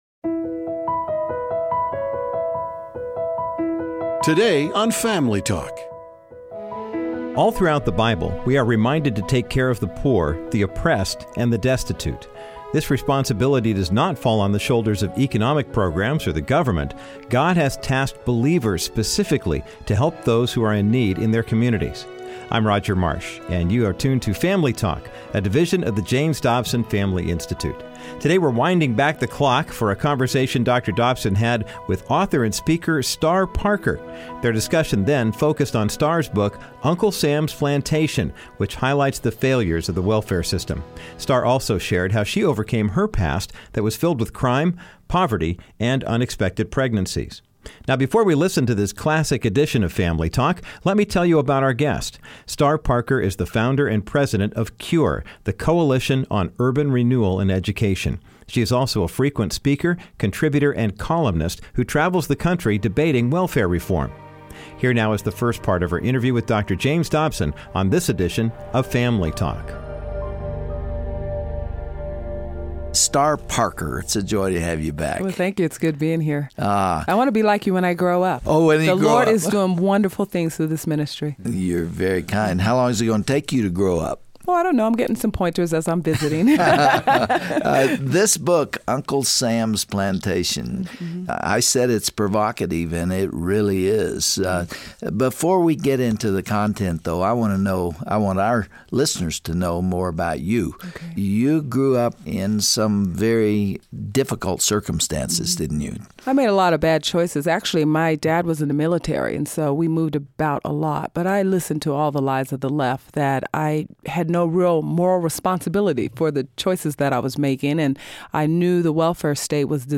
On this classic Family Talk broadcast, Dr. Dobson discusses the failures of the welfare system with Star Parker, author of Uncle Sams Plantation. She opens up about overcoming her past of poverty, addiction, and crime. Star also simplifies why the government is not truly helping the poor in this country.